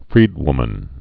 (frēdwmən)